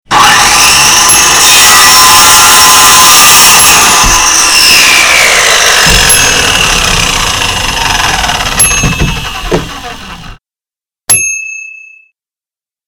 Table Saw Cutting Wood Sound Effect
Pristine LOUD recording of a table saw cutting wood for 10 seconds. Great for a shop scene, sound effect or even a ringtone.3 saw versions of different lengths and sounds available here.